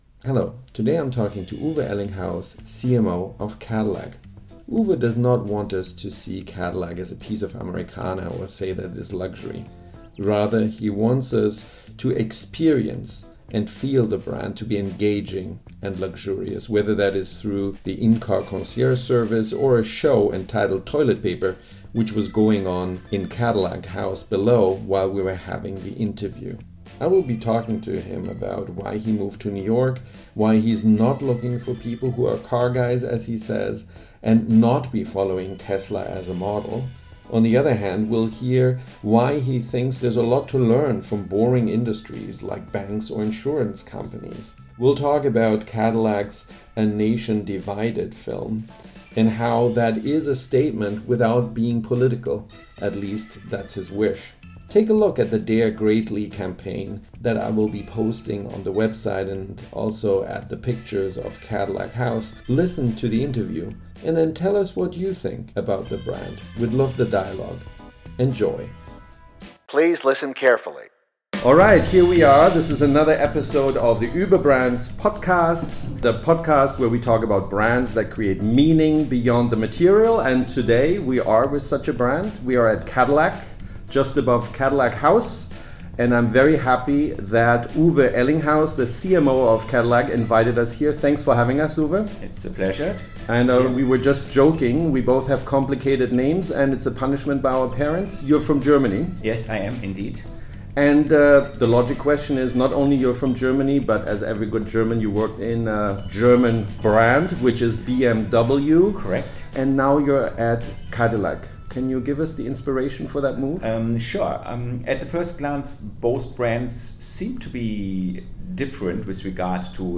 Cadillac – Resuscitation without Nostalgia nor Provocation? – Interview